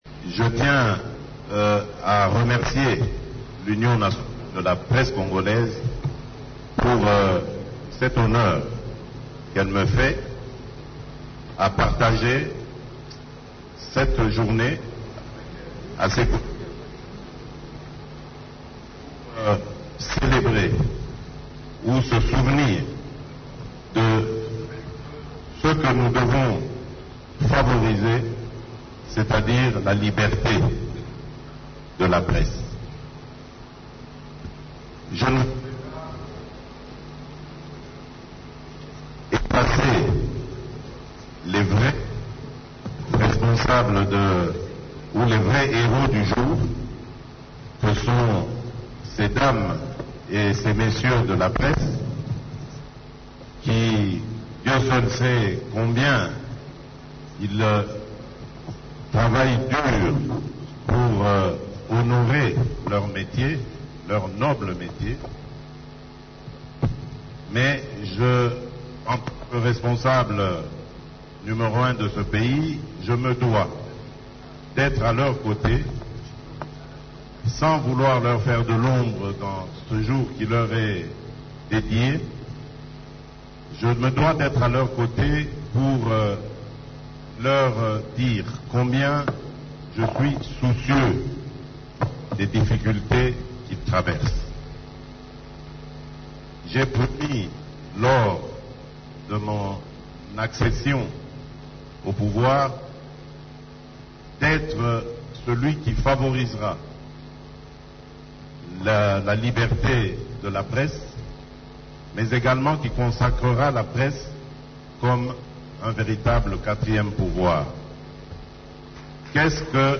Le Président de la République, Félix Tshisekedi, a invité tous les journalistes exerçant en RDC à observer l’éthique et la déontologie « pour exercer ce noble métier ». Lors de la célébration la journée mondiale de la presse, il a fait remarquer la menace des réseaux sociaux à laquelle les professionnels des médias devront faire face.